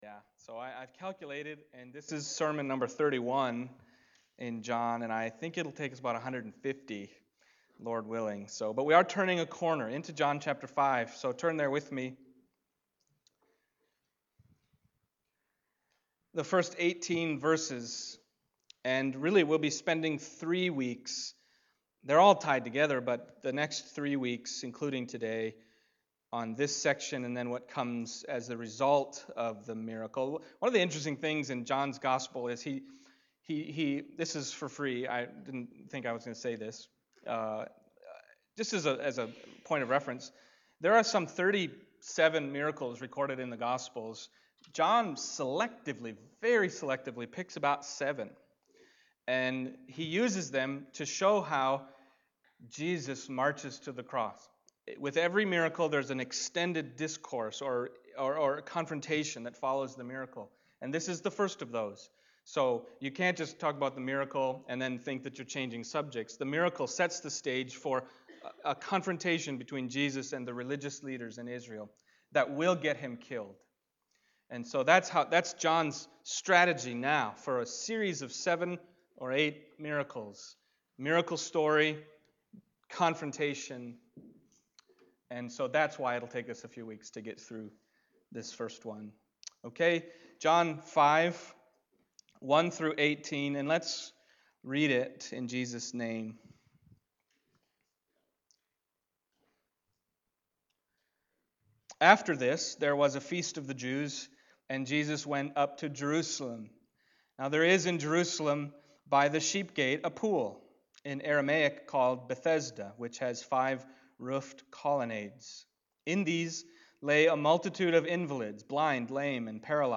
Passage: John 5:1-18 Service Type: Sunday Morning